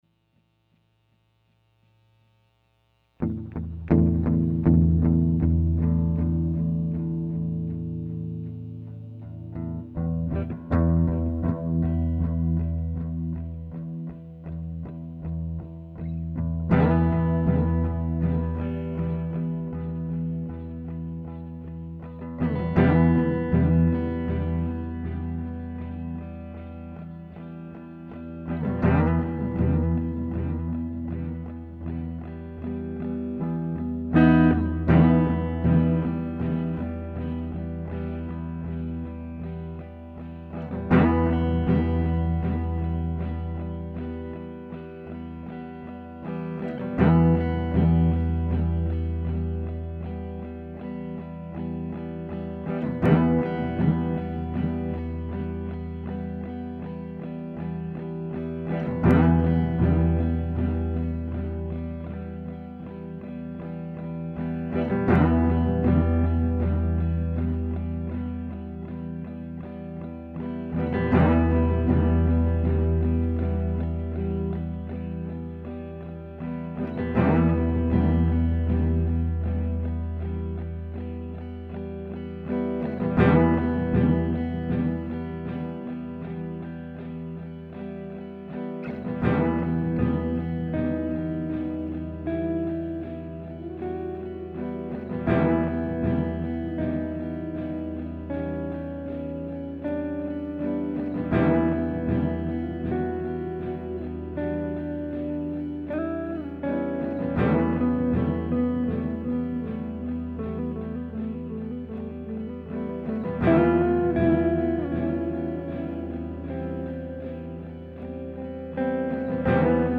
The intro’s nice but if you want to skip around 9:00 is good, then around 16:15 the riff is ‘matured’. The ending has a distored riff that’s been stuck in my head for a while.